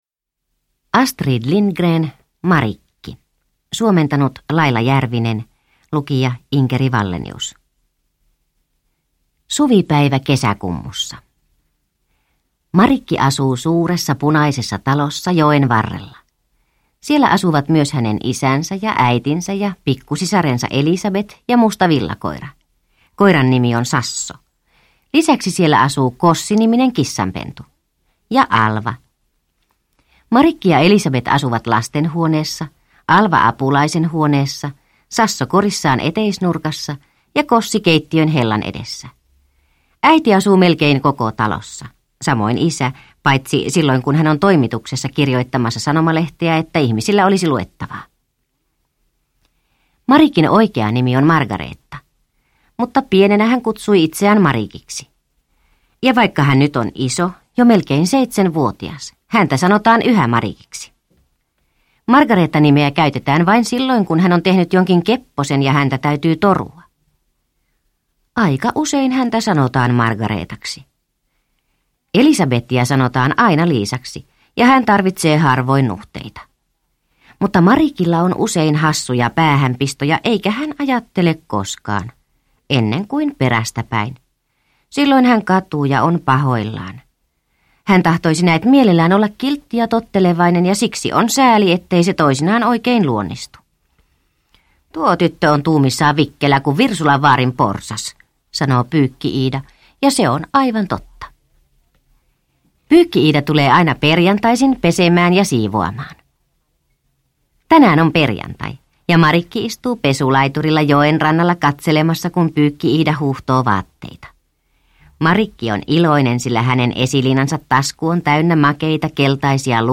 Marikki – Ljudbok – Laddas ner